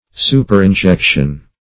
Superinjection \Su`per*in*jec"tion\, n. An injection succeeding another.
superinjection.mp3